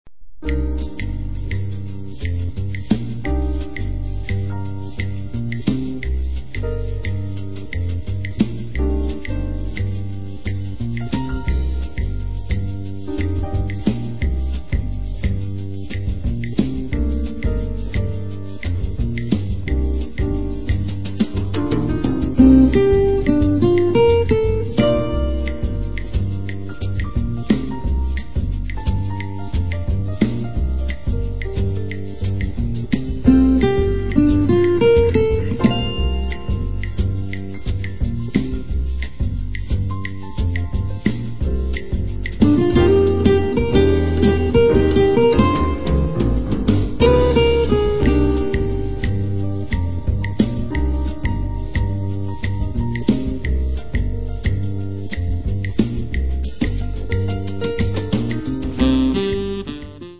Classical and acoustic guitars
Contralto and soprano sax
con un pianismo delicato, sussurrante